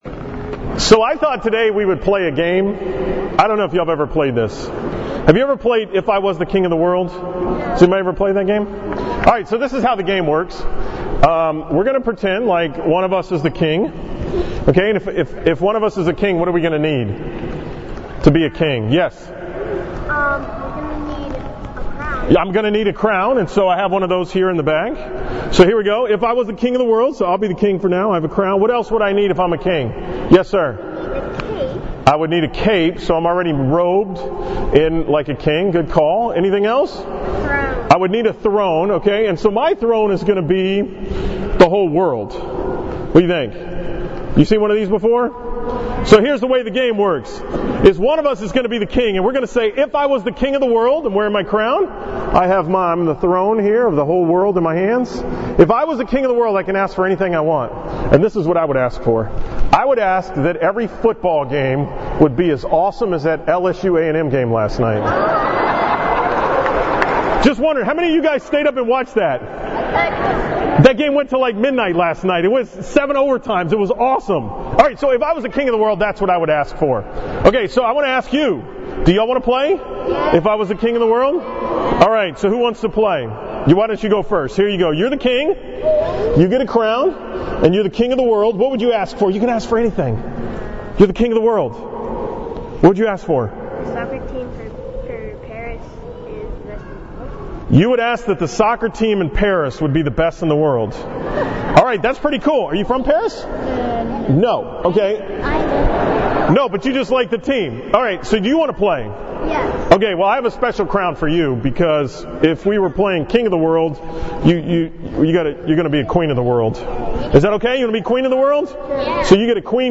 From the 9 am Mass at St. Martha's on Christ the King Sunday (November 25, 2018)